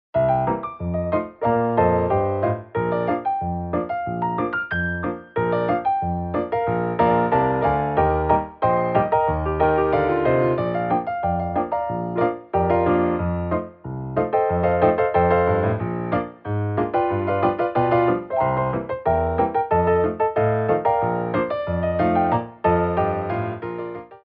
QUICK TEMPO